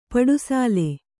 ♪ paḍu